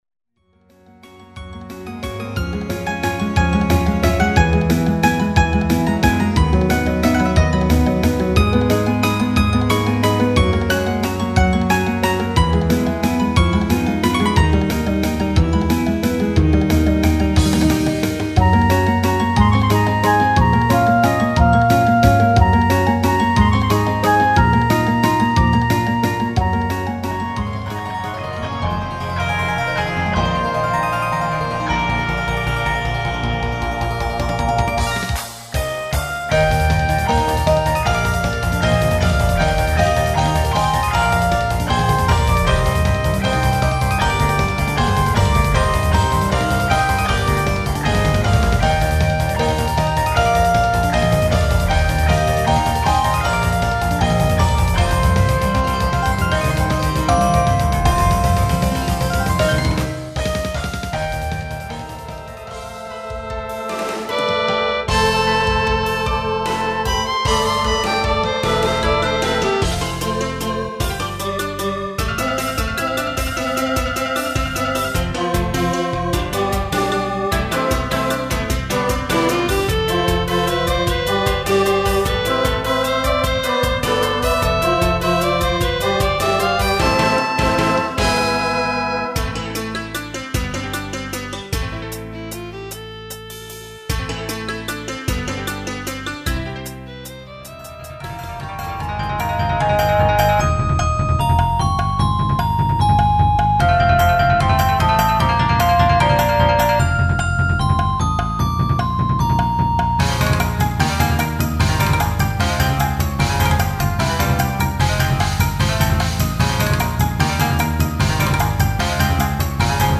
・オリジナルインストルメンタルミニアルバム